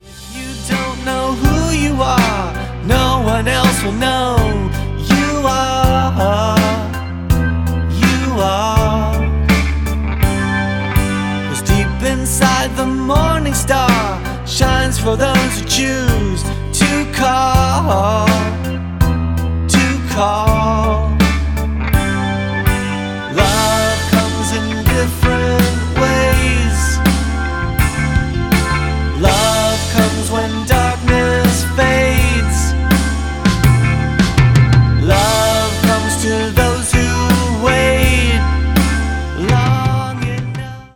demo